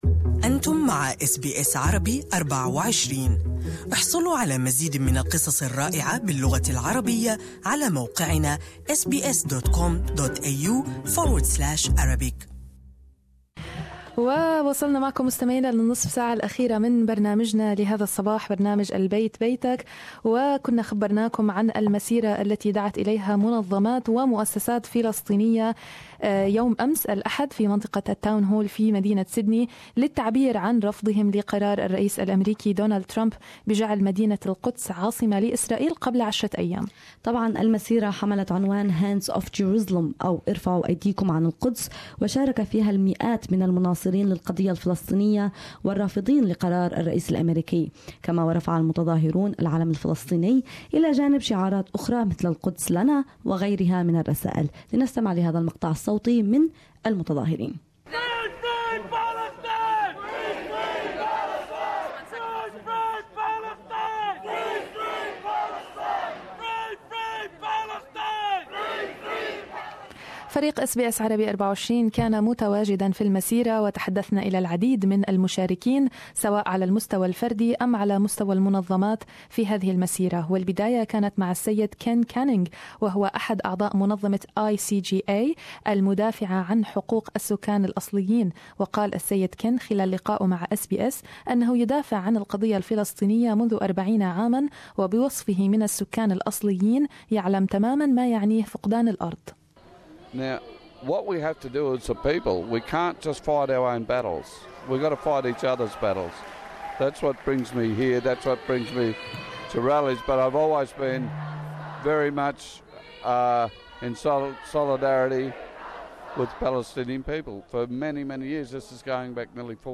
"Hands off Jerusalem" rally for Palestine in Sydney